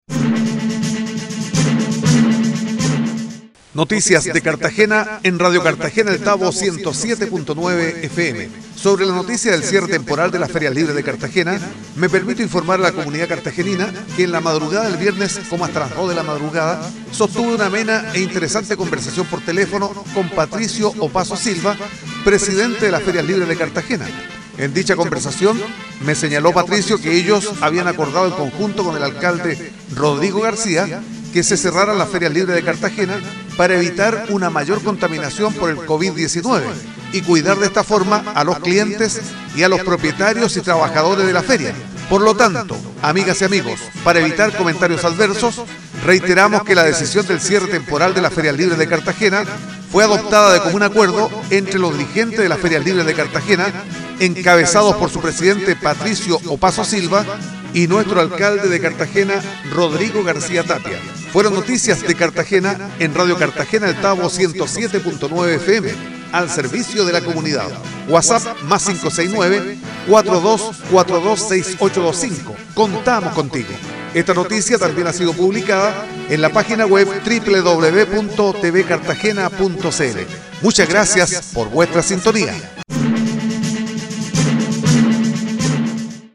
Noticias de Cartagena en Radio Cartagena El Tabo 107.9 FM